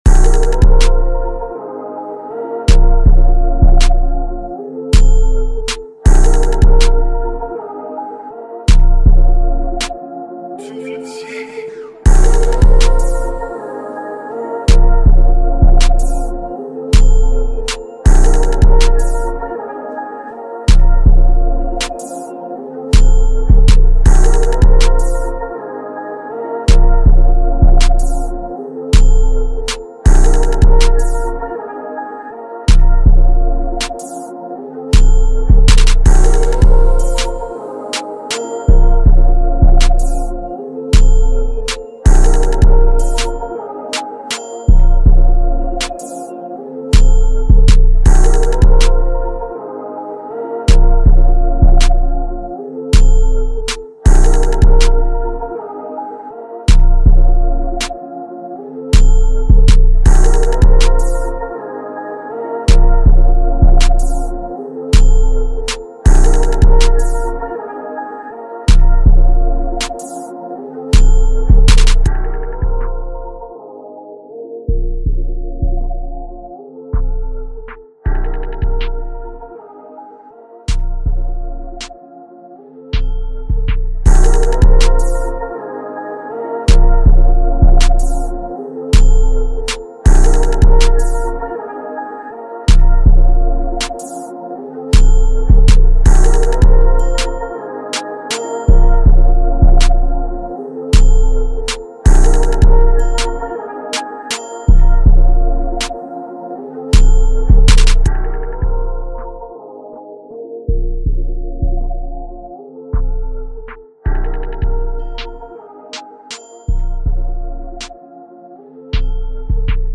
This is the instrumental of the new song.